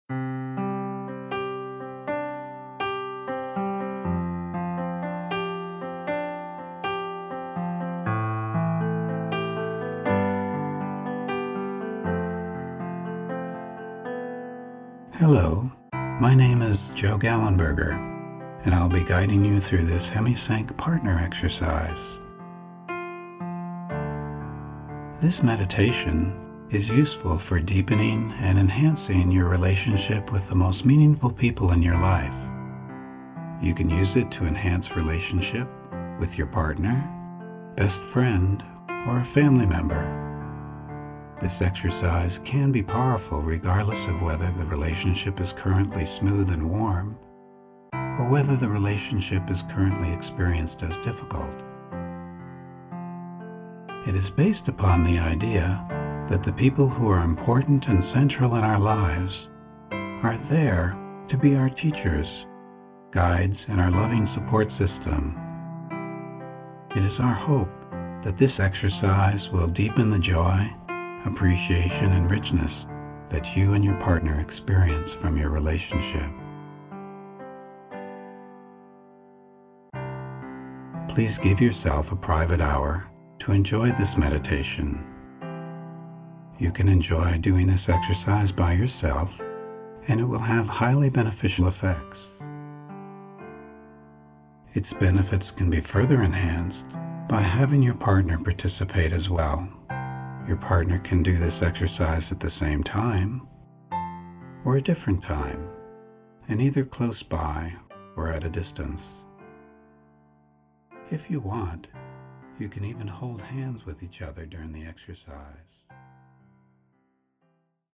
Enhance and deepen your meaningful relationships with this verbally guided Hemi-Sync® exercise.
Partners_Meditation_Sample.mp3